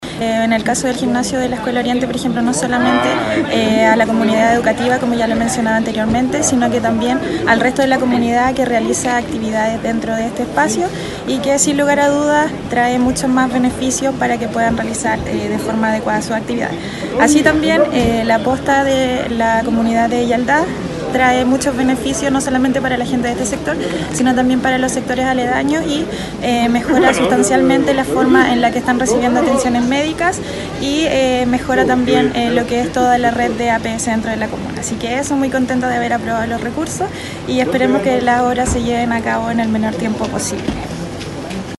En tanto la Consejera Regional, Daniela Méndez, destacó la aprobación de recursos adicionales para ambos proyectos, que se venían gestionando desde hace bastante tiempo y que, con su materialización, beneficiarán a un importante número de personas de la comuna de Quellón: